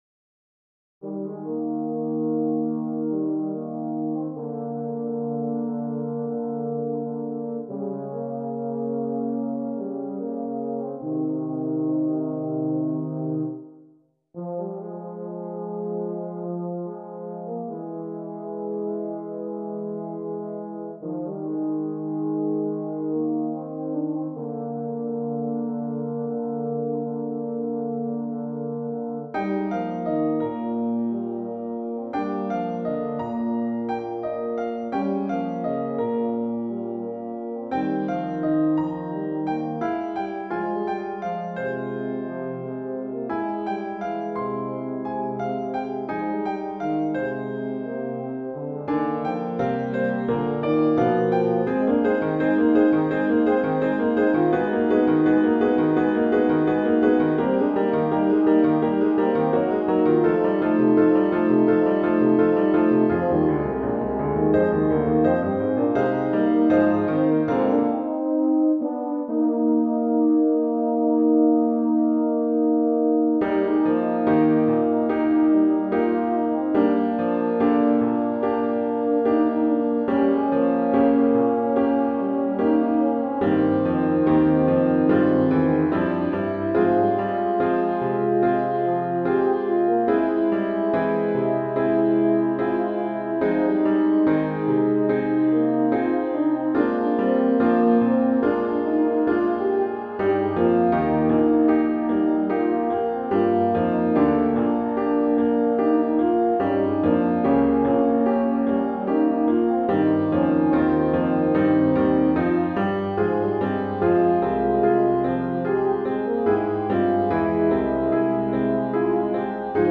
Voicing: Euphonium Duet